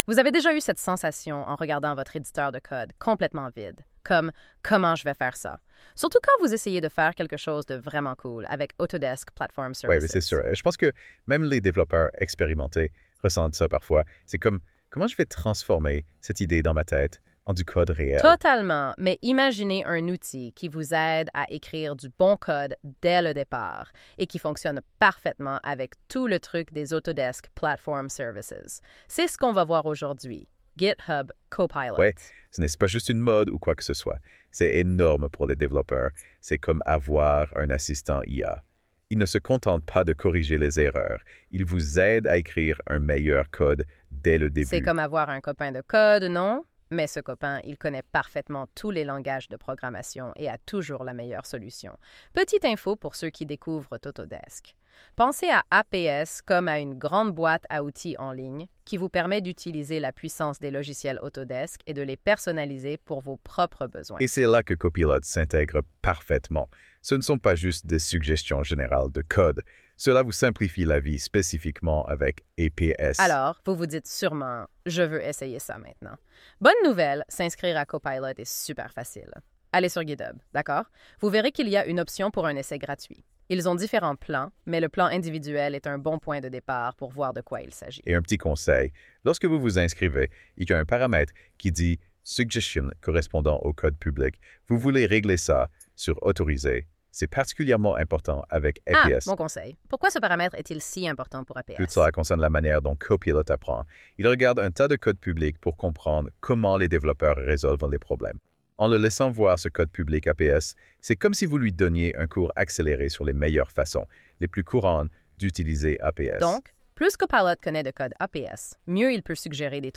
écoutez la conversation en français ci-dessous pour vous faire une idée générale puis lisez ensuite l’article détaillé: